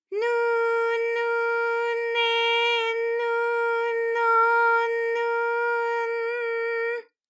ALYS-DB-001-JPN - First Japanese UTAU vocal library of ALYS.
nu_nu_ne_nu_no_nu_n_n.wav